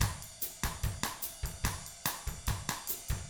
146BOSSAF2-R.wav